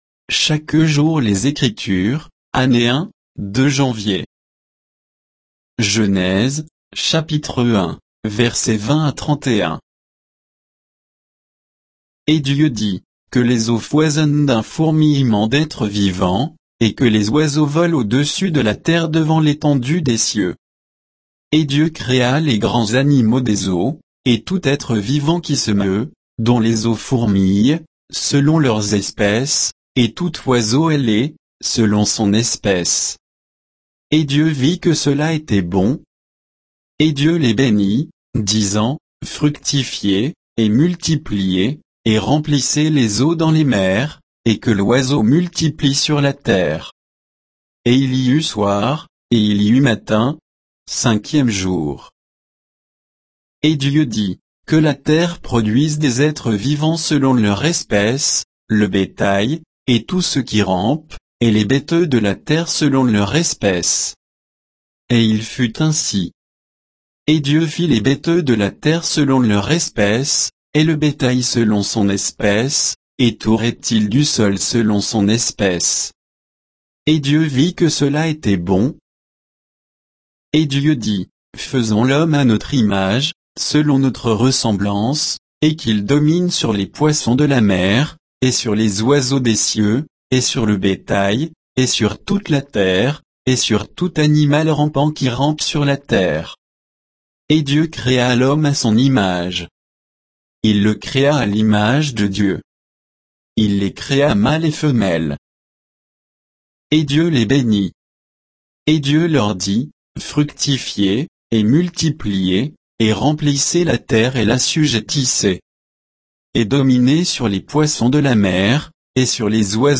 Méditation quoditienne de Chaque jour les Écritures sur Genèse 1